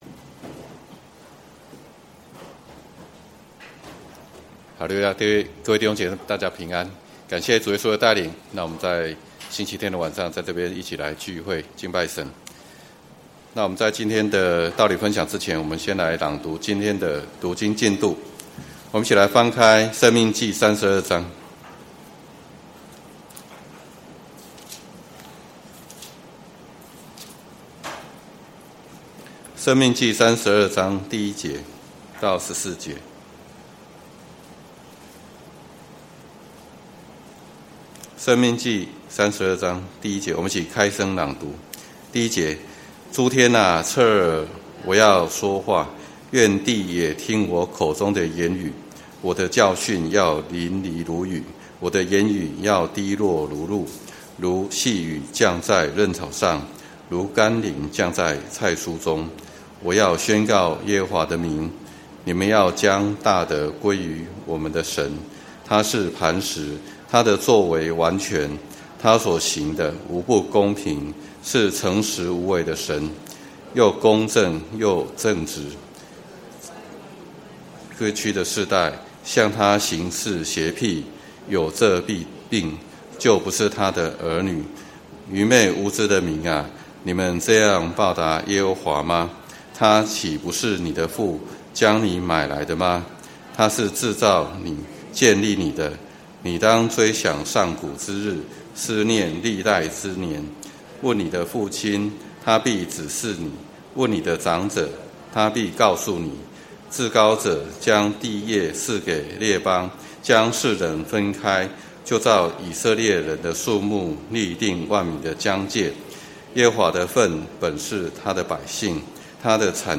在試煉中成長-講道錄音